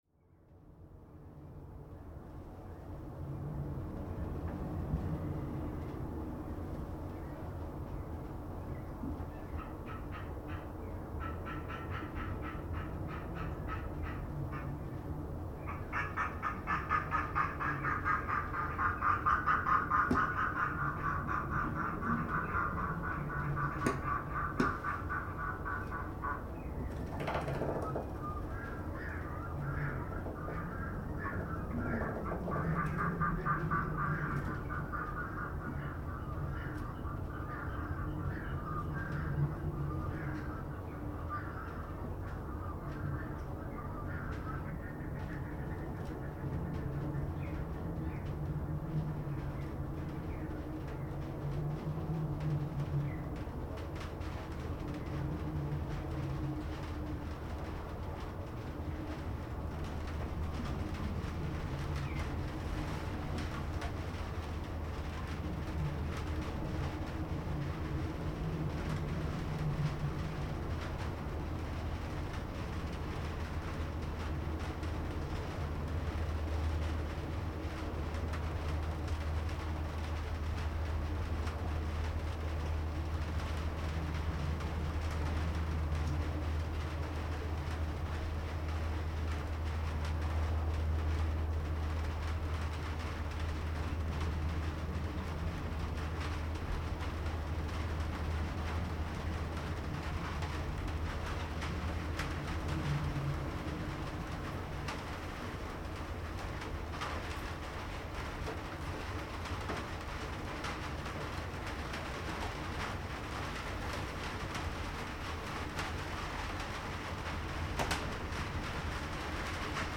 I spend two nights in the area and tried to record surrounding ambient through soaking wet windscreens.
The second one was in the bird watching shelter.
The following recording is a short part of that moment while shower passes over. Most windows on the shelter were open so birds surround are audible. Background noise is not a traffic, it is the surf at nearby south coast, about 3 km away.